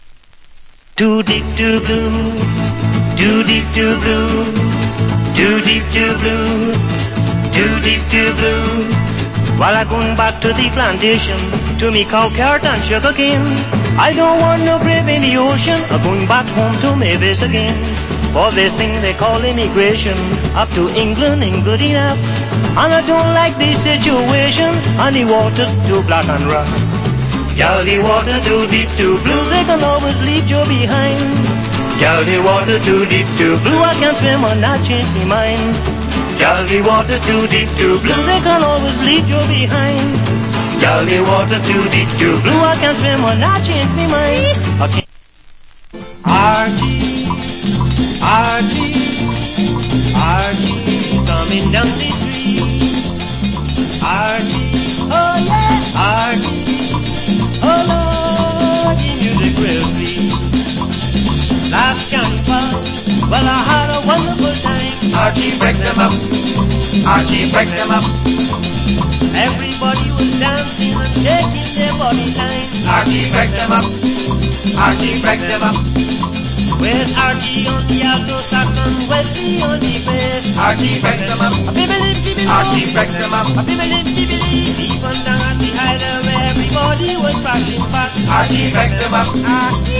Nice Calyoso Vocal.